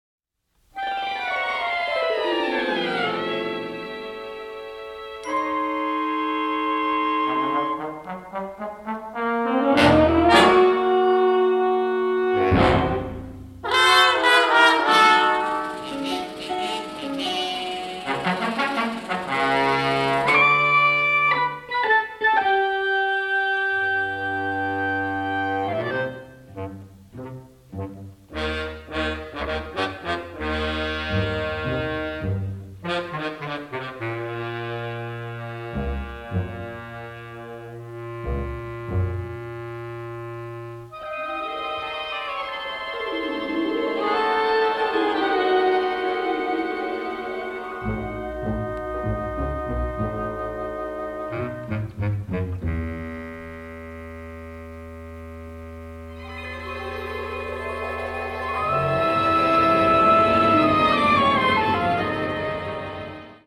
rousingly patriotic score
recognizable war anthems mixed with shades of sarcasm